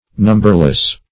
Numberless \Num"ber*less\, a.